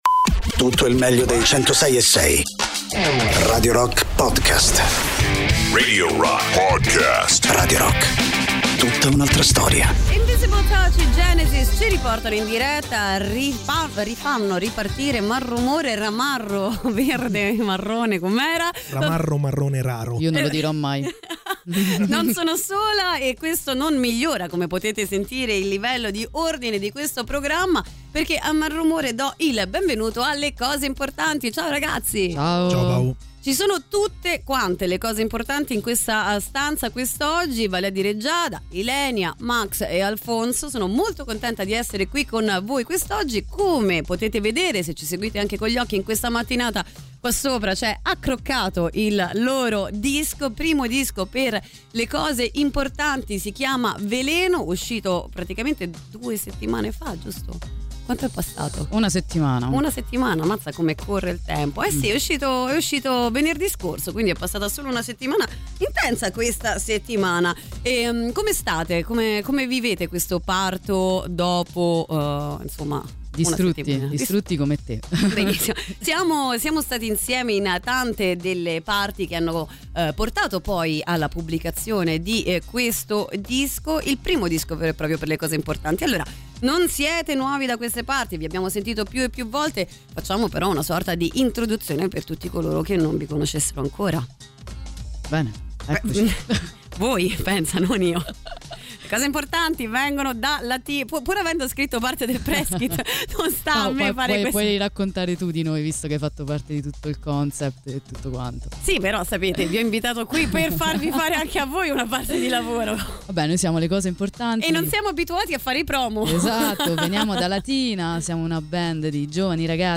Interviste: Le Cose Importanti (01-12-24)